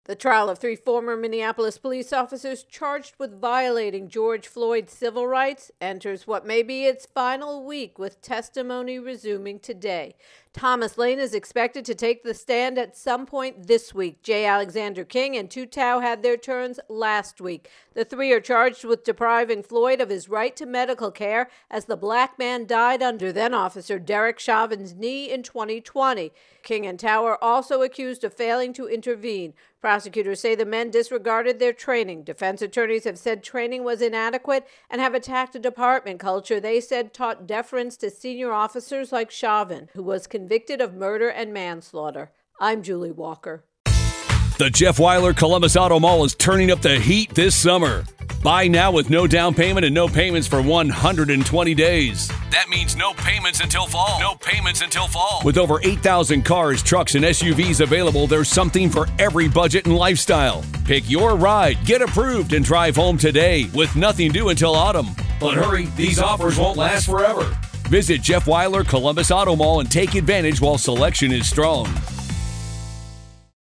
George Floyd Officers Civil Rights Intro and Voicer